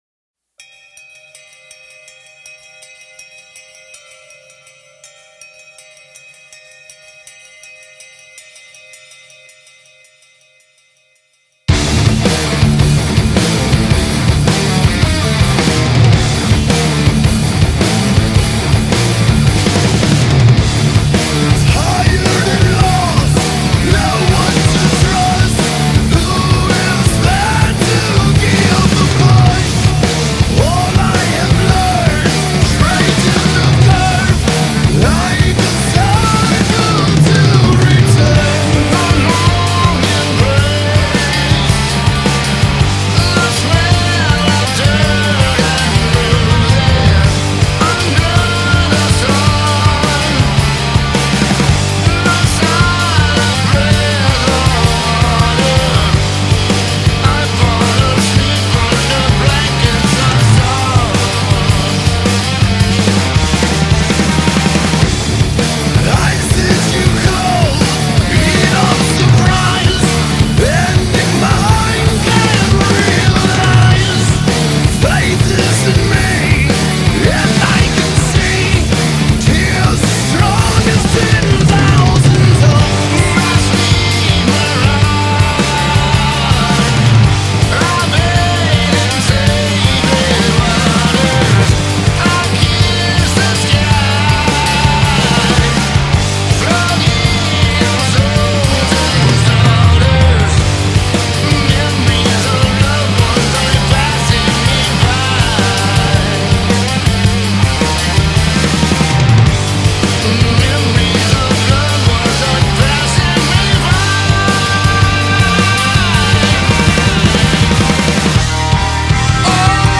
Heavy Metal, Progressive Metal, Stoner Metal, Hard Rock